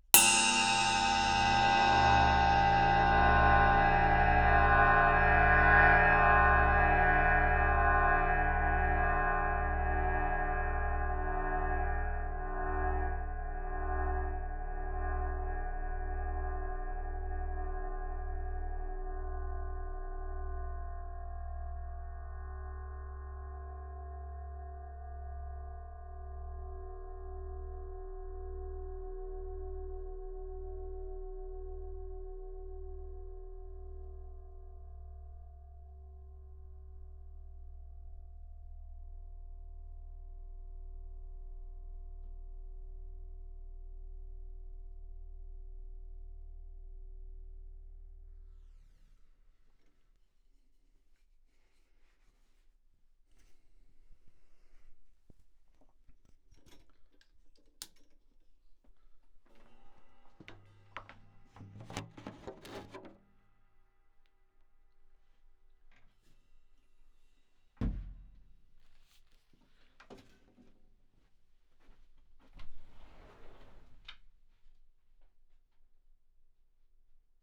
With that in mind, here are a pair of hits reversed, hard panned and with an added splash of reverb to create an eerie, ‘something bad is about to happen’ kind of sound:
flying-cymbal-4.wav